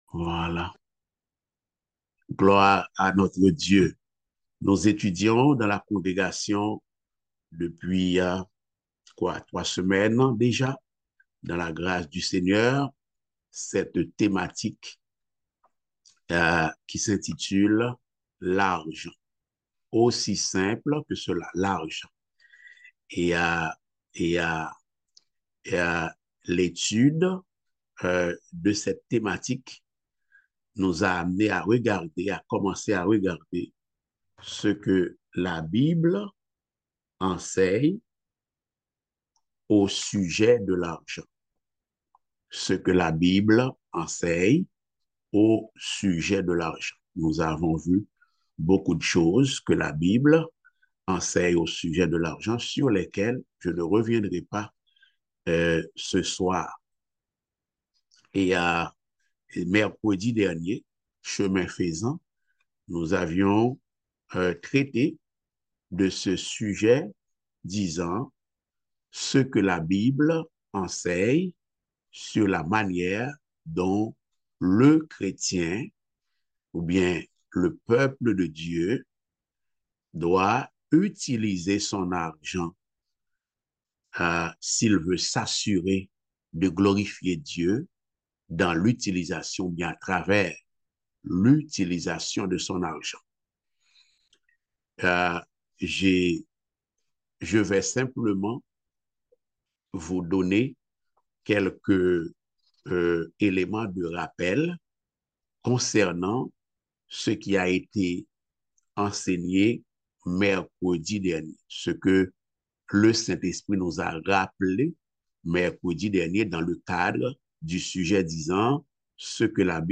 Passage: 2 Corinthiens 9.6-15 Type De Service: Études Bibliques « La souveraineté de Dieu dans la création part. 4 La souveraineté de Dieu dans la création.